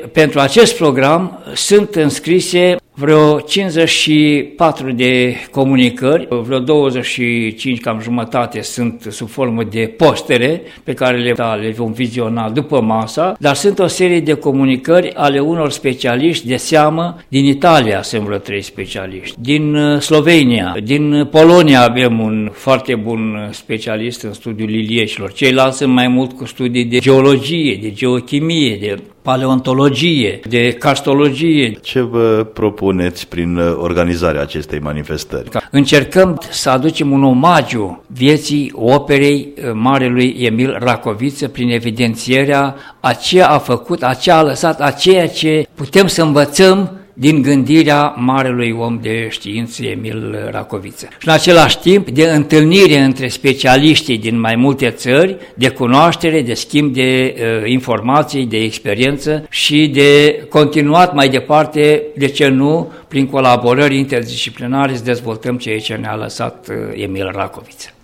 La Sala de Conferințe a Hotelului Afrodita din stațiunea Băile Herculane au început astăzi lucrările Simpozionului internațional de Biospeleologie și Carstologie Teoretică și Aplicată, eveniment dedicat aniversării celor 150 de ani de la nașterea cunoscutului naturalist român Emil Racoviță.